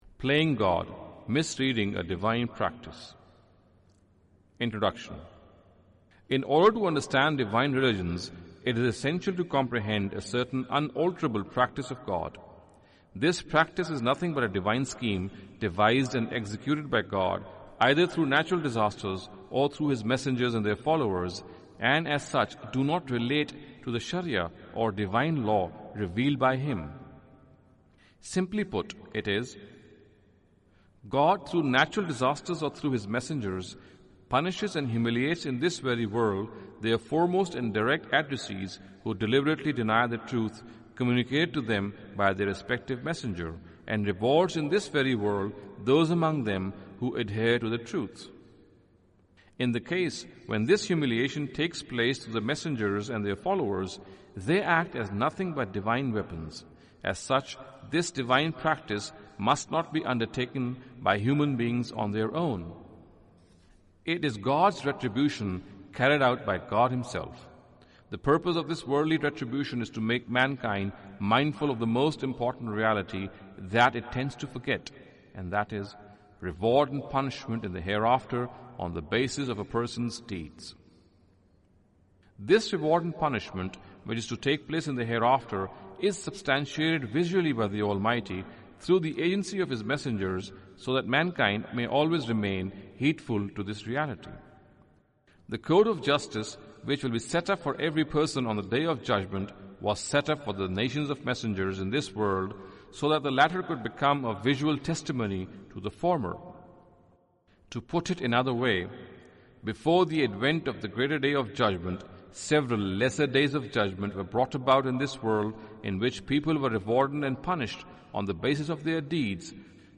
Audio book of English translation of Javed Ahmad Ghamidi's book "Playing God".